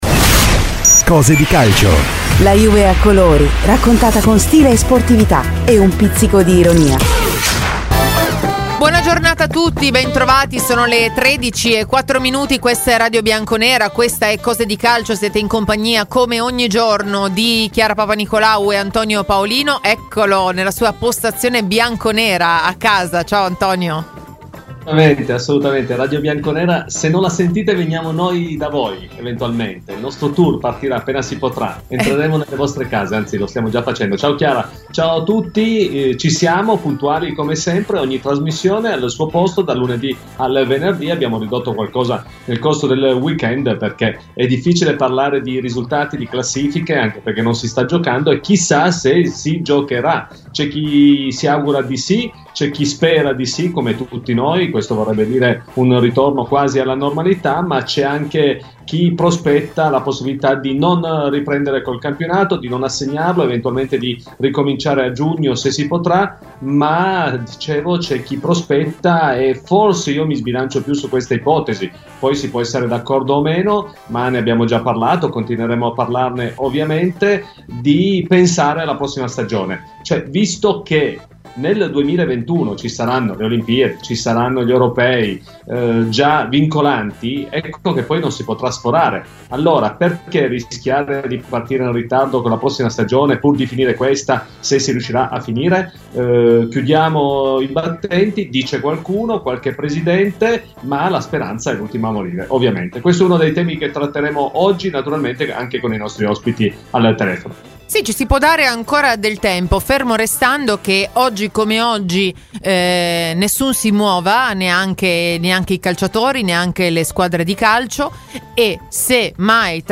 (collegamento skype)